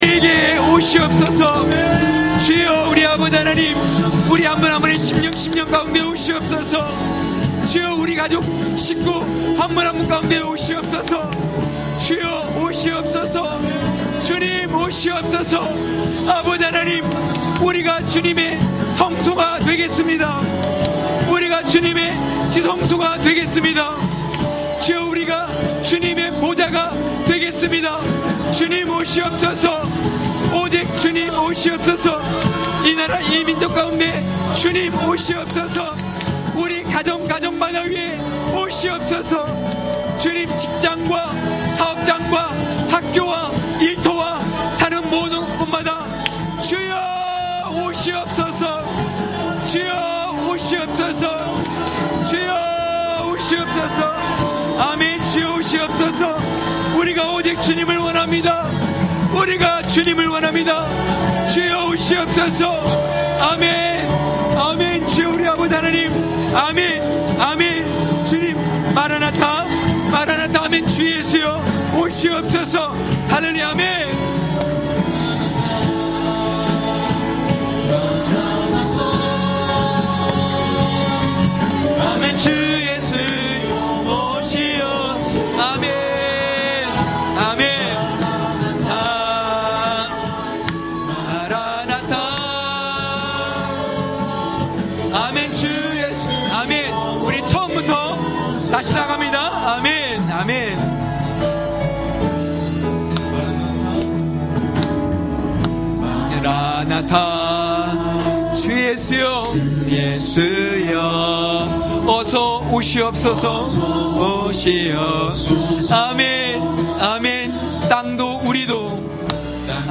강해설교 - 14.초막을 지나 백향목 시대로!!(느8장13~18절).mp3